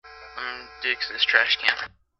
Trash Can